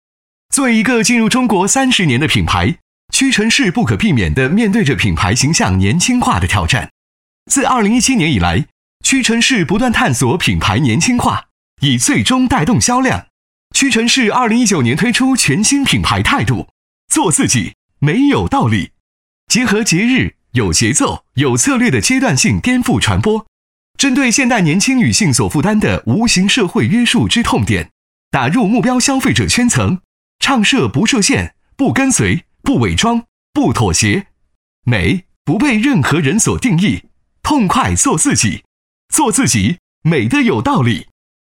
屈臣氏男157号
轻松自然 MG动画